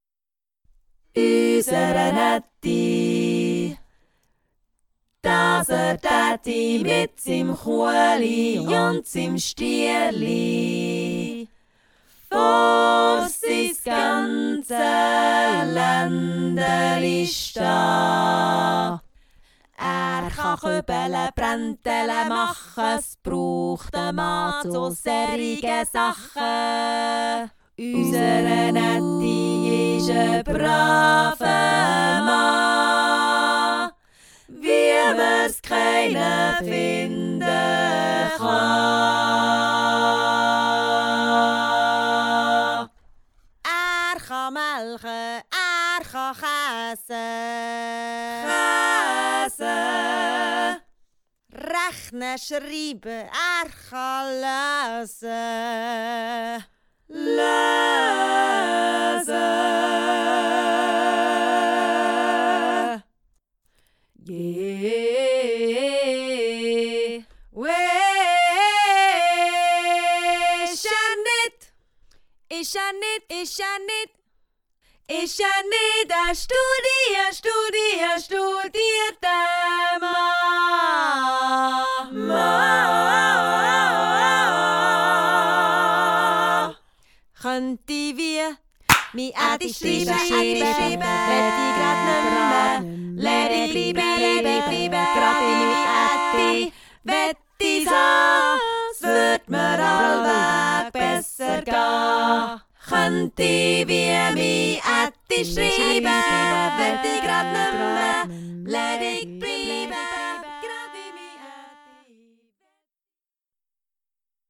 Arrangements // a Cappella
SSAA